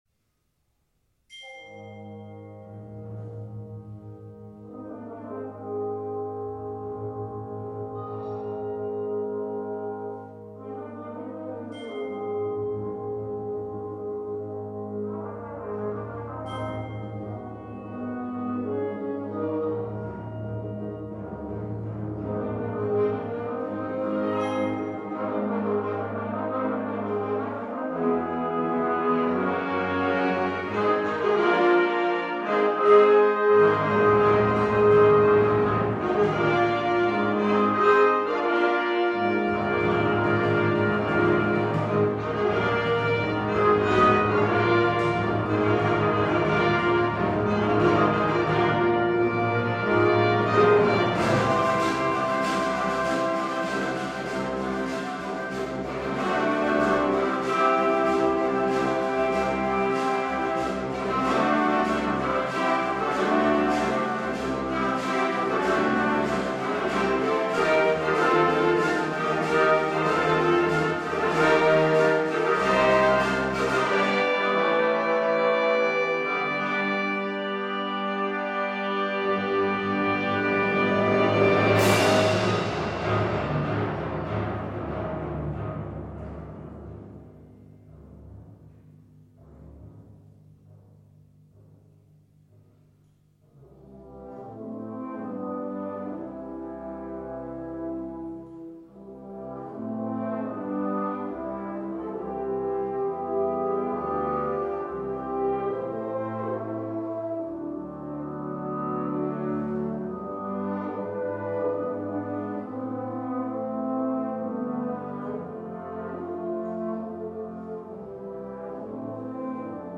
Genre: Band
Instrumentation
Euphonium
Timpani